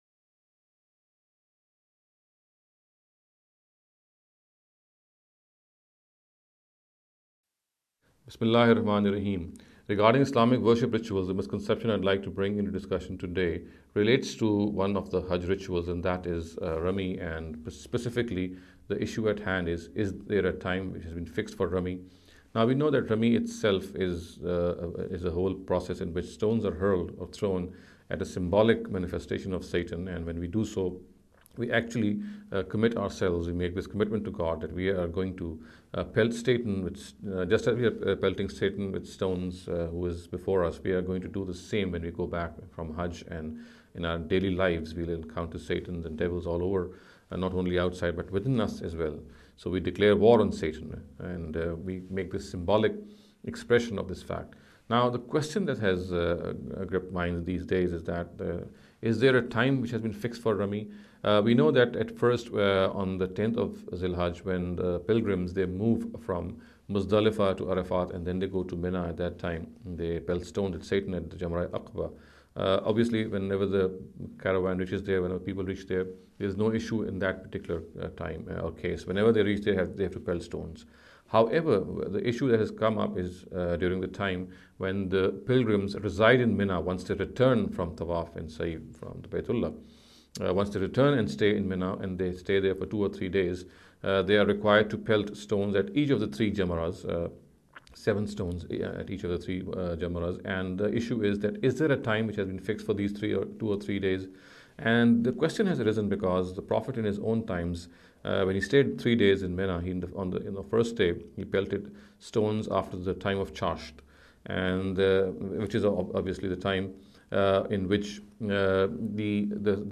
This lecture series will deal with some misconception regarding the Islamic Worship Rituals.